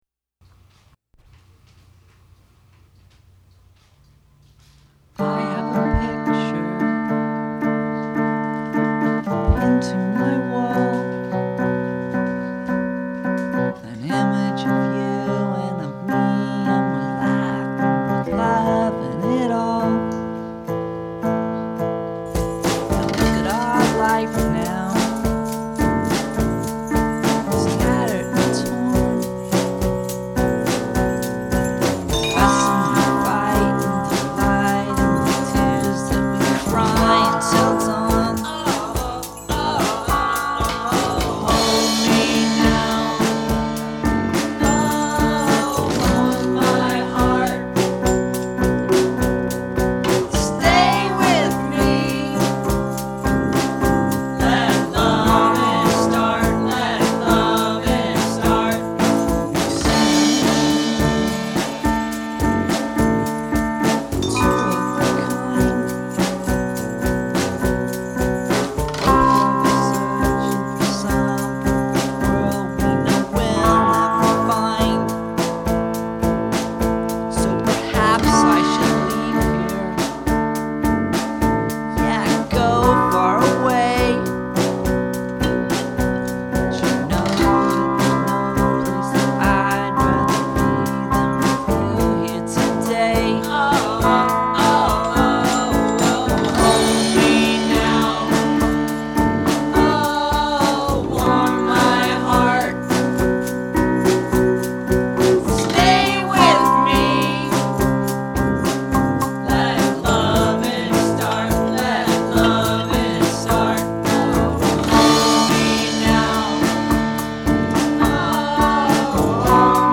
on an 8-track